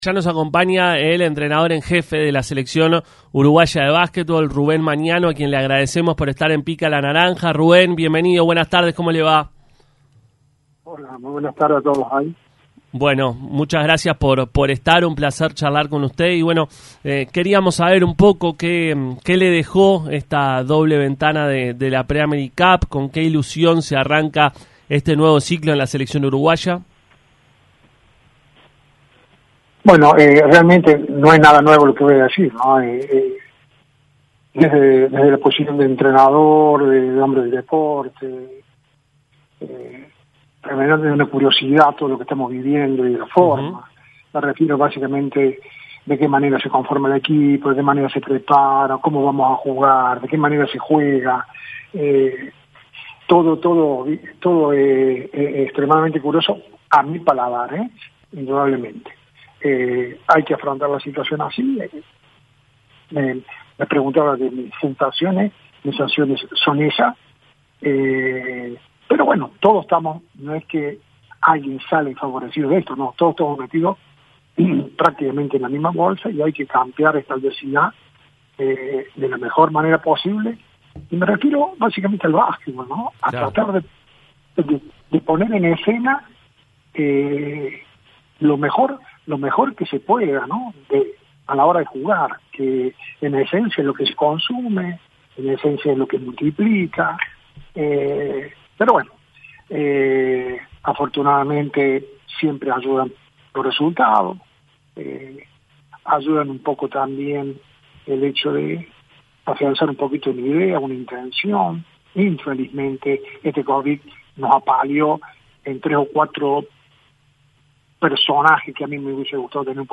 Rubén Magnano, entrenador de la selección uruguaya de básquetbol, estuvo en el programa Pica la naranja y habló de muchos temas. Destacó el presente de muchos de los jóvenes.